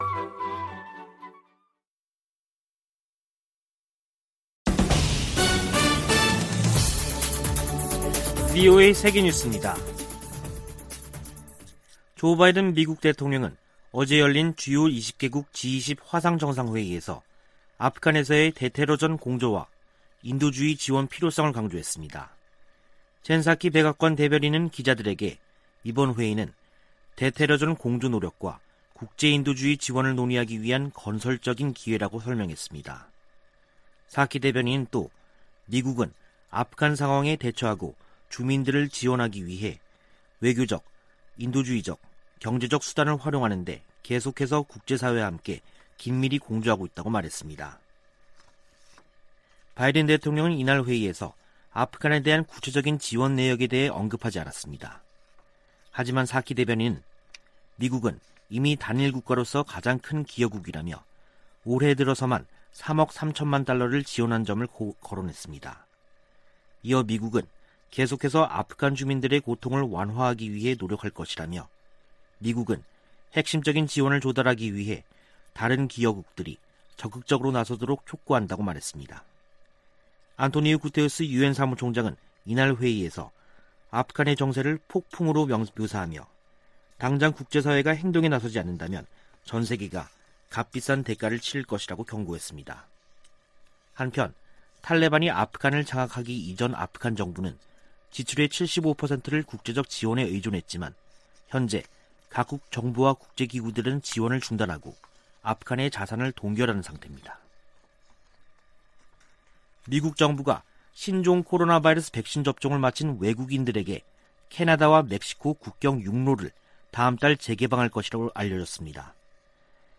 VOA 한국어 간판 뉴스 프로그램 '뉴스 투데이', 2021년 10월 13일 2부 방송입니다. 미국이 적대적이지 않다고 믿을 근거가 없다는 김정은 북한 국무위원장 발언에 대해, 미 국무부는 적대 의도가 없다고 강조했습니다. 제이크 설리번 미 국가안보좌관이 워싱턴에서 서훈 한국 국가안보실장과 북한 문제 등을 논의했습니다. 유엔 안보리 대북제재위원회로부터 제재 면제를 승인 받은 국제 지원 물품들이 북한 반입을 위해 대기 상태입니다.